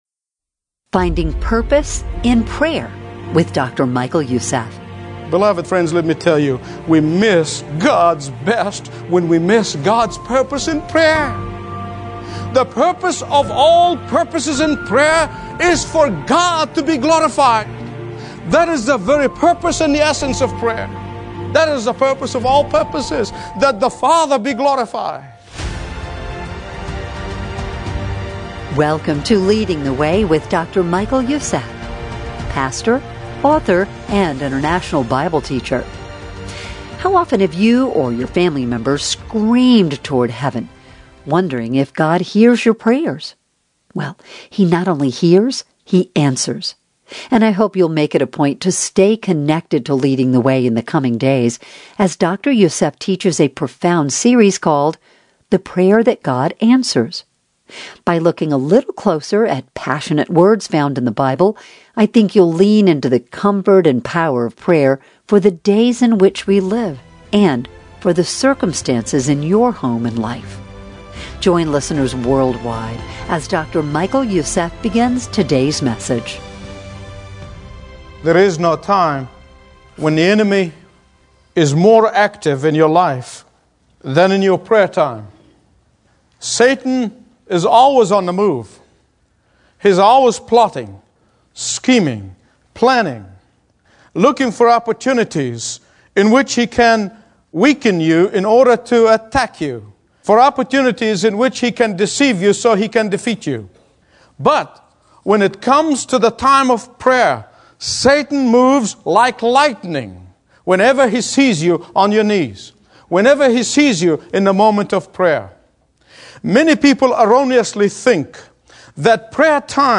Daily Bible Teachings